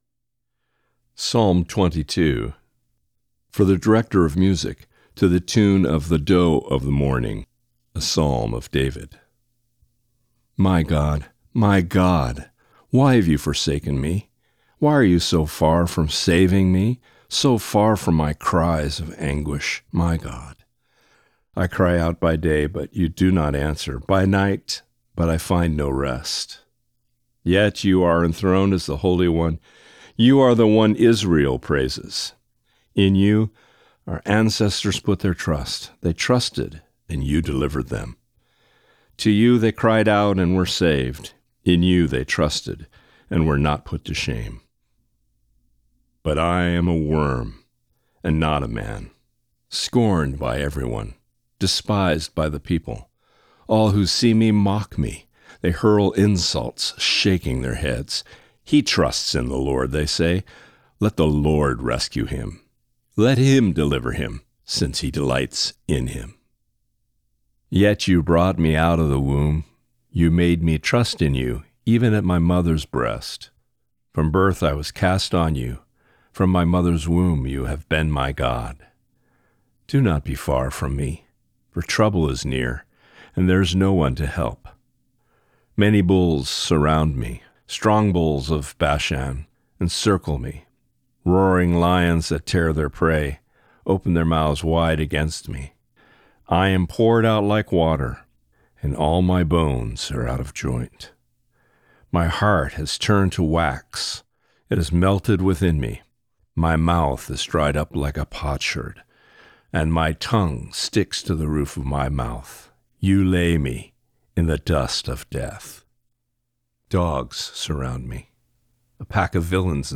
Reading: Psalm 22 (NIV)*